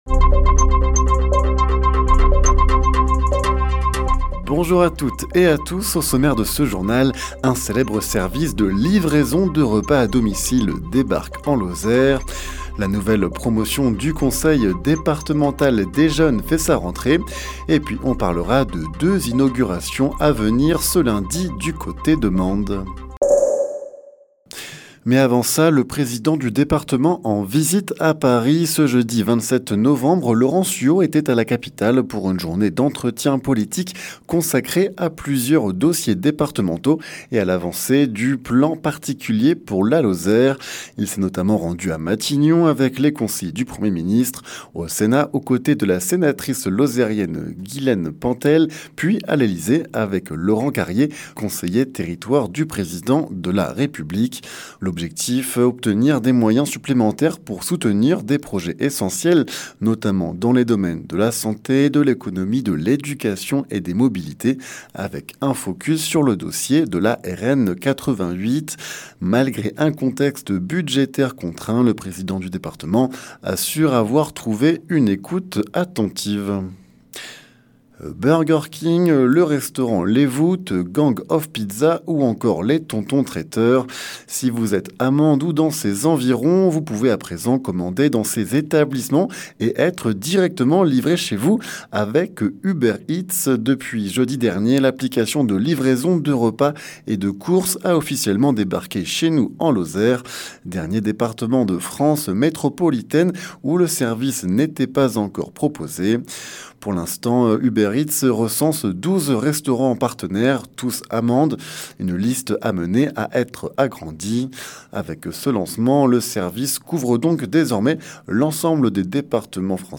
Les informations locales
Le journal sur 48FM présenté par